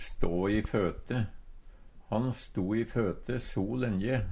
stå i føte - Numedalsmål (en-US)